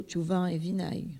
Localisation Bois-de-Céné
Catégorie Locution